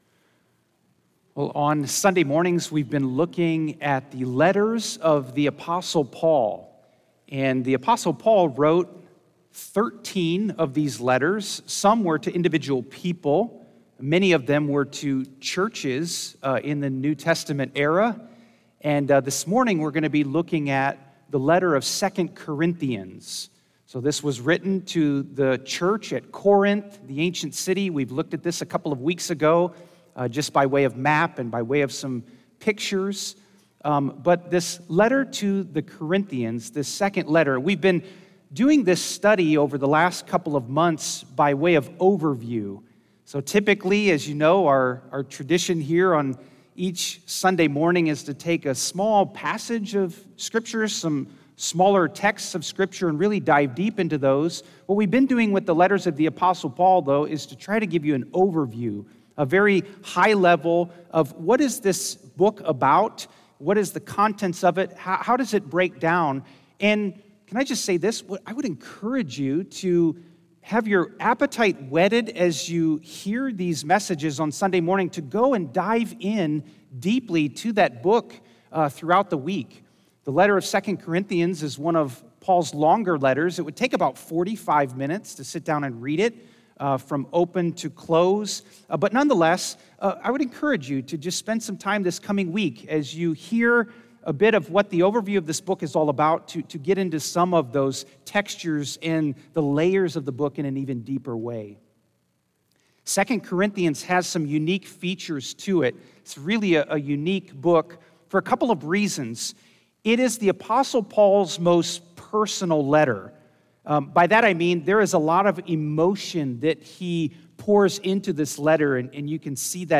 This podcast shares the sermons preached from the pulpit at Lexington Community Church located in Lexington Illinois.